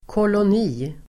Uttal: [kålån'i:]